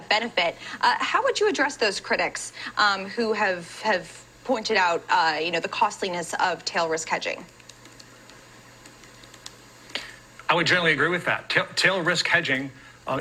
Example for regular interview audio.
Interview_audio.wav